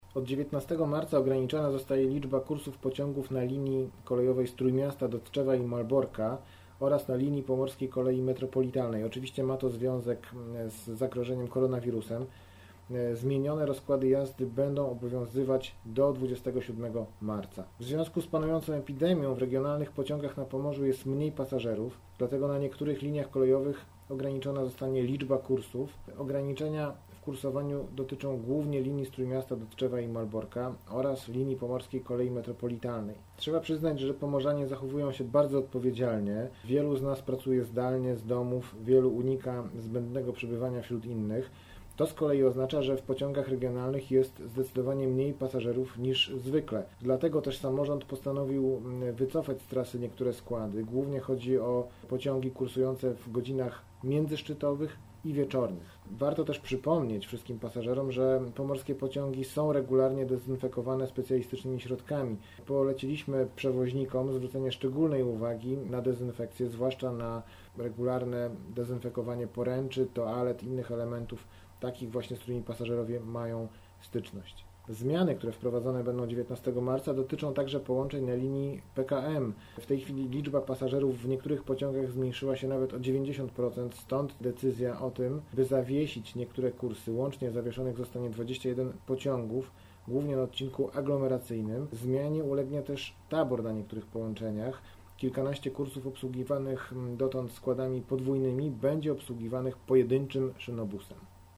Posłuchaj komunikatu głosowego